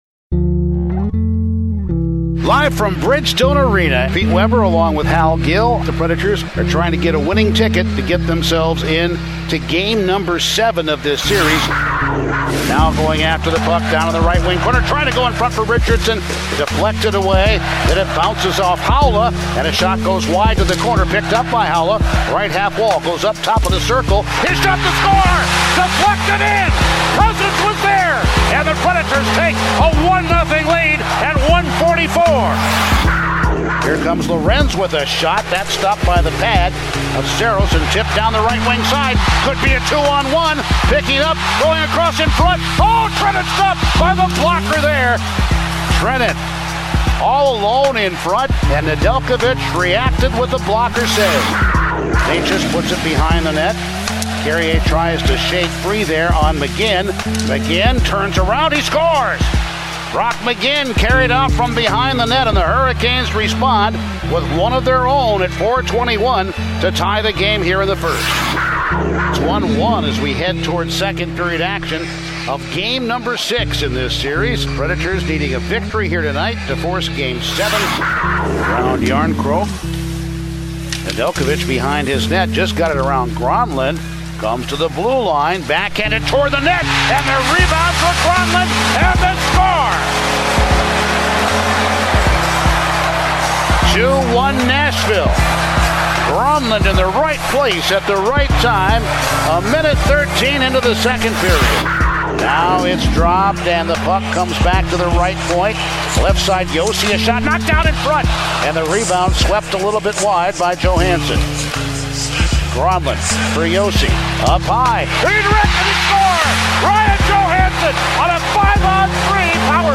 Full radio highlights from the Predators' final game of the 2021 season as the Hurricanes win the series in overtime.